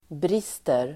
Uttal: [br'is:ter]